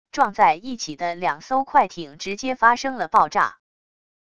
撞在一起的两艘快艇直接发生了爆炸wav音频